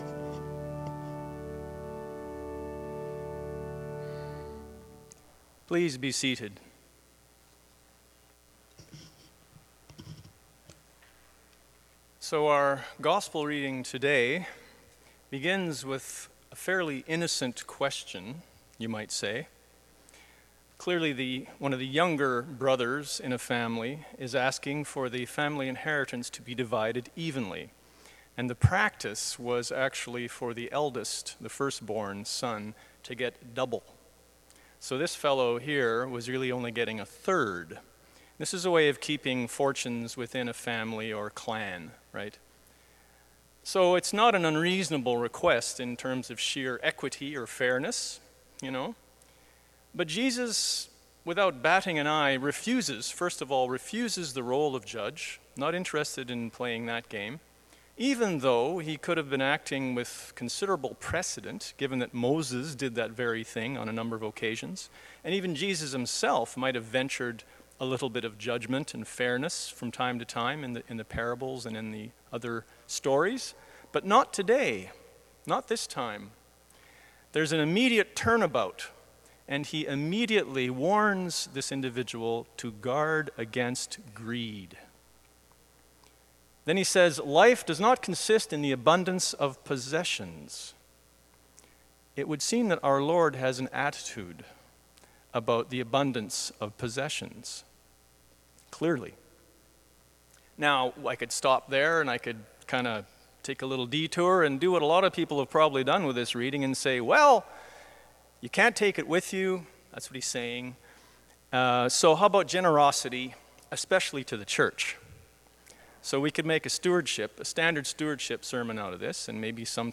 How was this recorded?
Sermon: 9.15 a.m. service